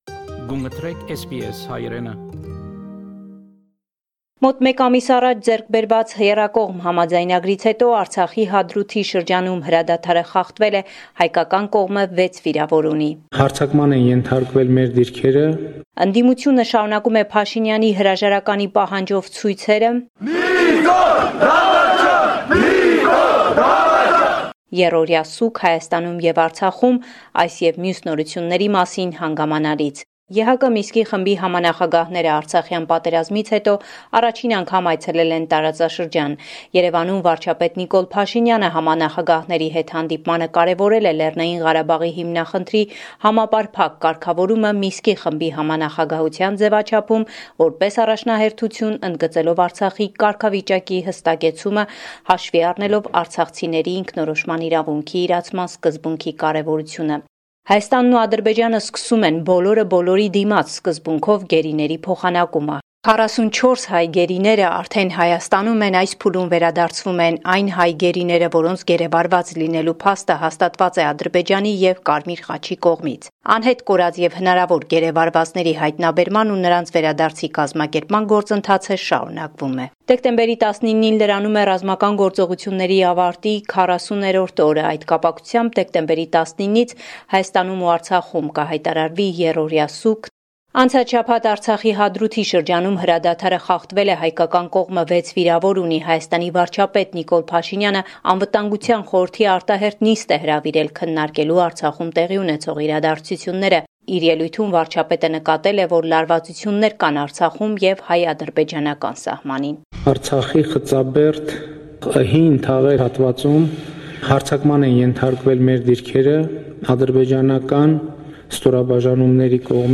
News from Armenia, Artsakh and the Diaspora